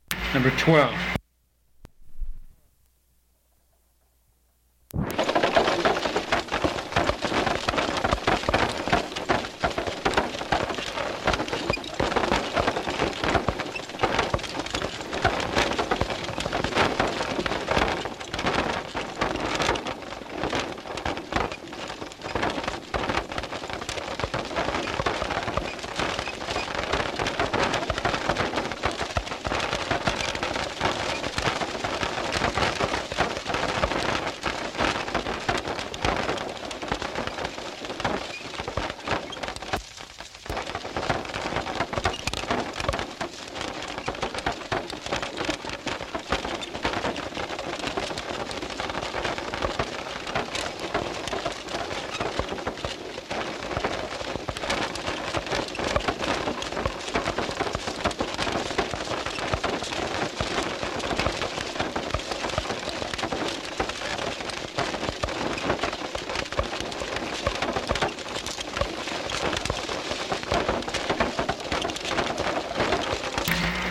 古老的马车 " G5212有盖马车
描述：有褶皱的马车，金属嘎嘎声和吱吱声。声音扭曲。
我已将它们数字化以便保存，但它们尚未恢复并且有一些噪音。